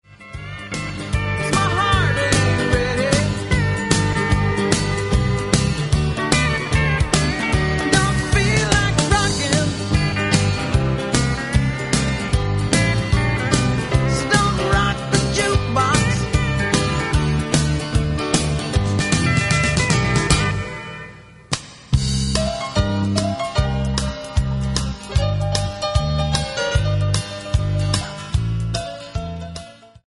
MP3 – Original Key – Backing Vocals Like Original